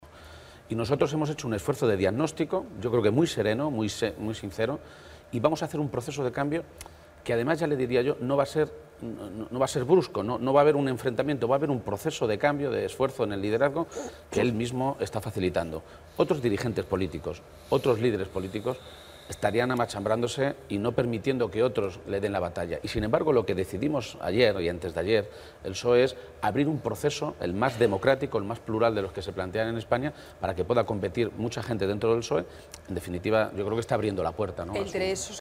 García-Page se pronunciaba de esta manera durante una entrevista en el programa Espejo Público de Antena 3 Televisión, en el que aseguraba que “los socialistas volvemos a decirle con claridad a los españoles lo que pueden esperar de nosotros en materia fiscal, de empleo y de defensa del Estado del Bienestar frente al desmantelamiento de los servicios públicos esenciales que están llevando a cabo los Gobiernos del PP en la nación y en la mayoría de las comunidades autónomas”.
Page-entrevista_Antena_3-2.mp3